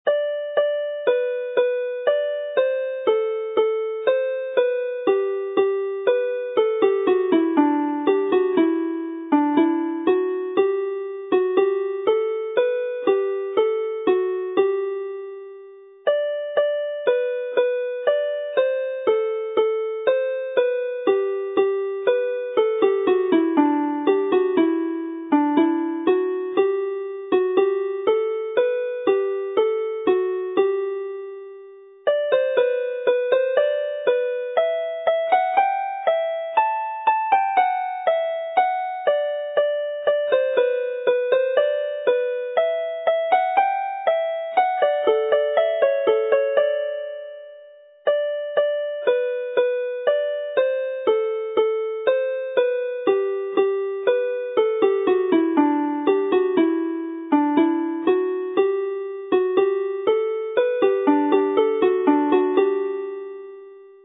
mp3 + cordiau